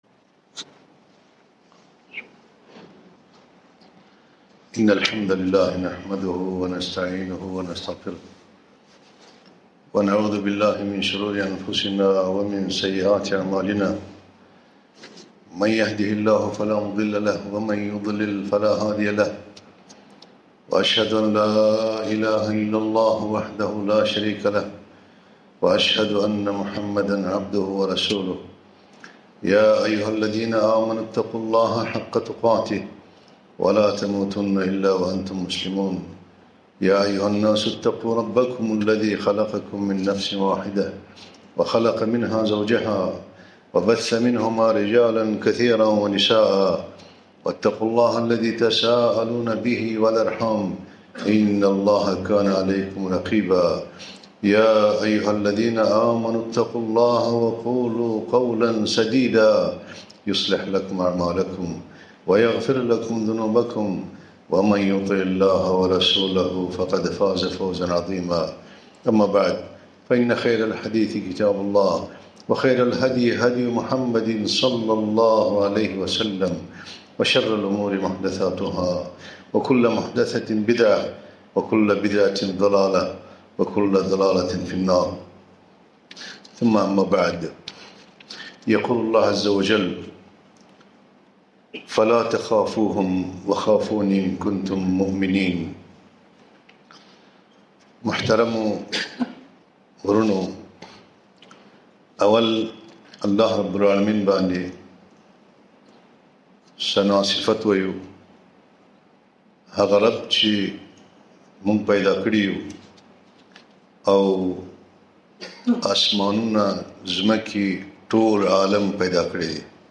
خطبة - الخوف من الله عز وجل ( باللغة بالشتو)